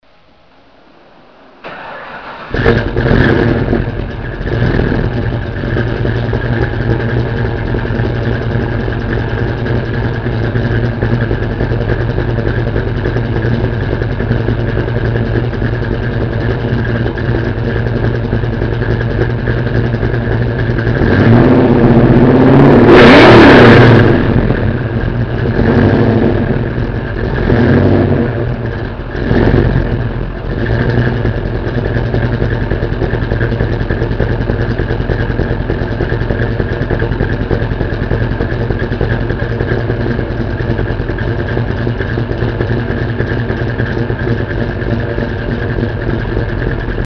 Mustang Exhaust Clips
Mustang Exhaust Sound/Movie Clips Page: